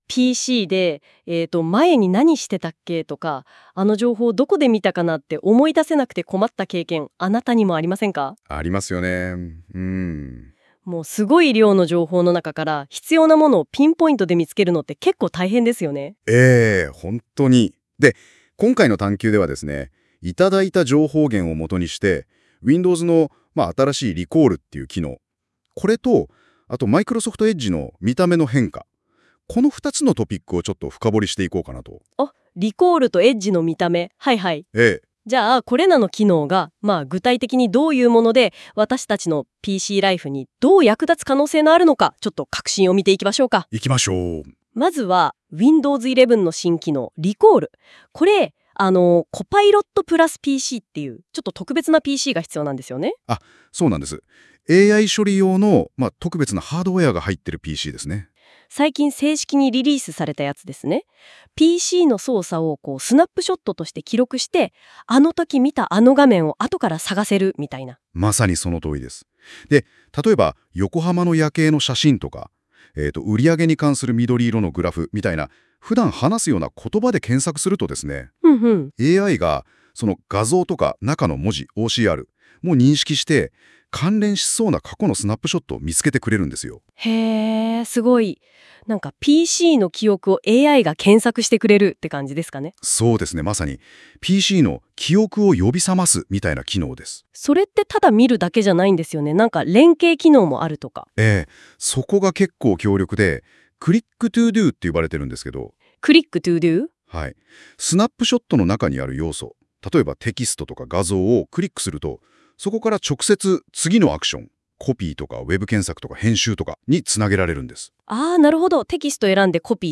人のポットキャスト番組聞いてお兄さんとお姉さんの楽しいポッドキャスト番組に仕上げやがって！！